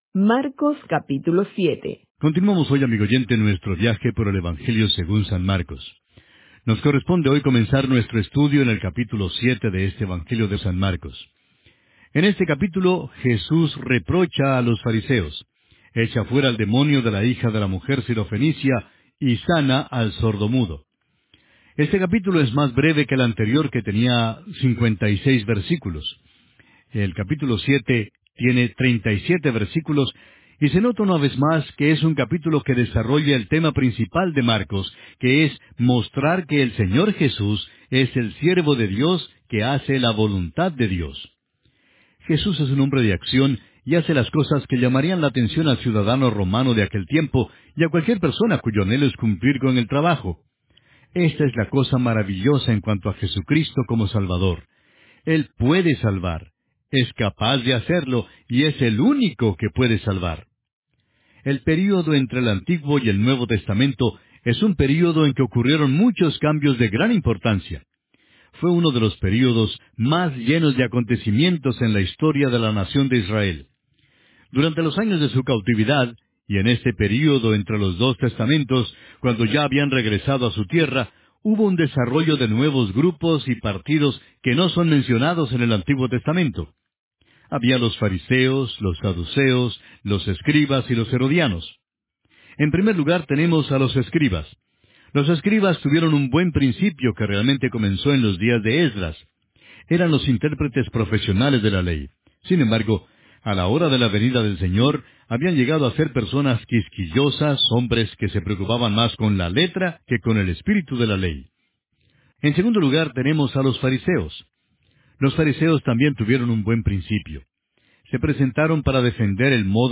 It’s important to clarify this is not Dr. J Vernon McGee's voice.